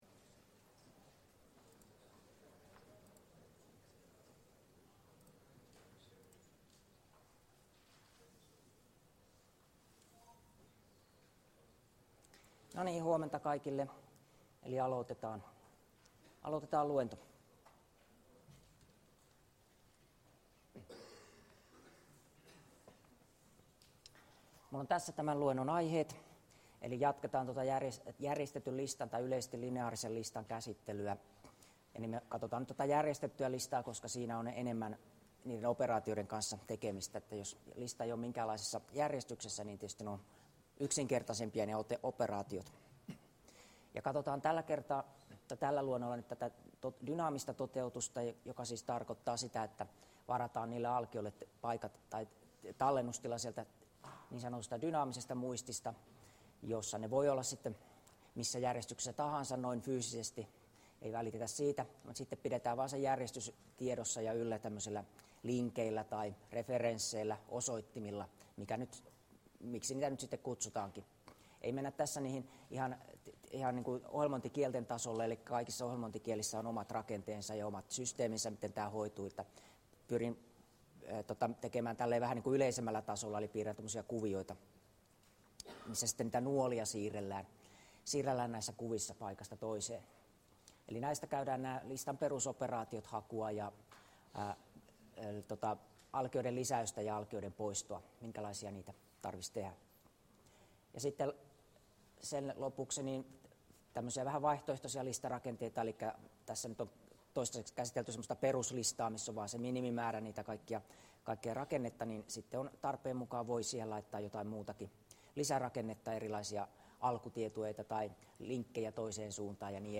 Luento 6 — Moniviestin